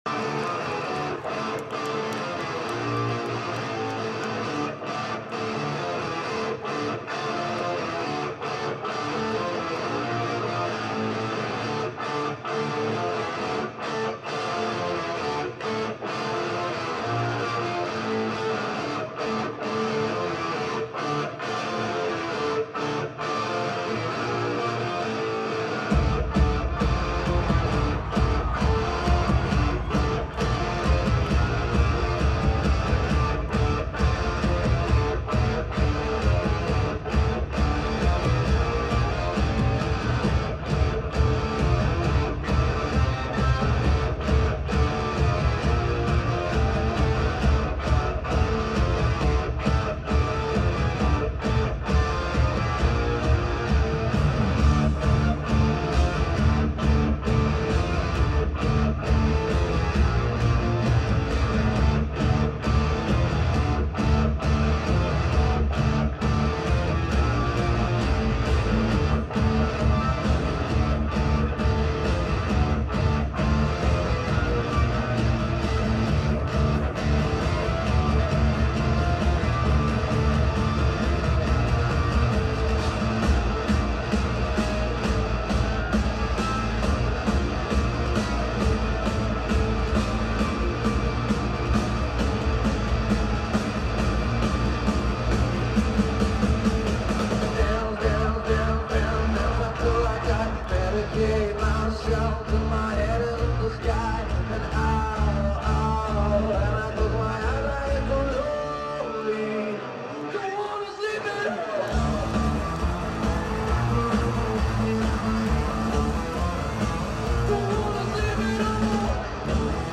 Ariake arina in Tokyo(03.12.2023)